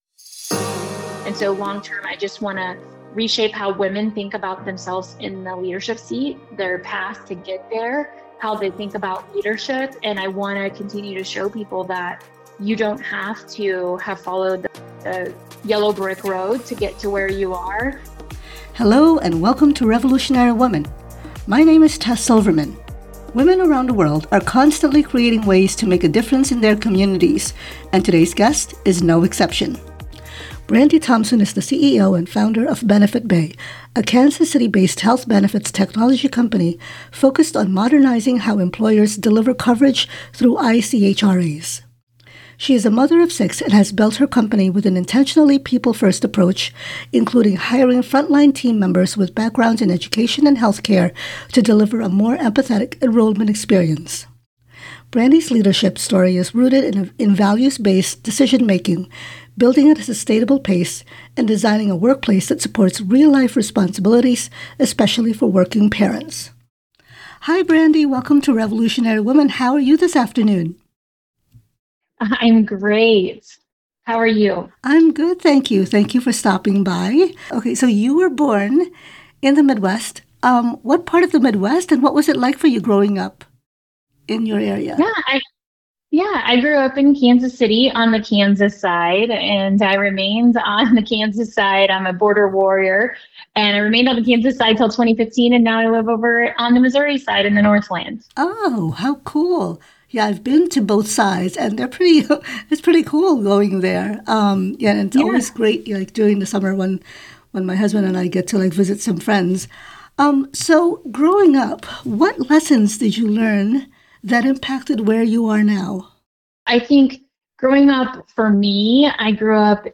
A female CEO shares her journey through leadership, motherhood, and career growth, and how she built a business without a traditional path.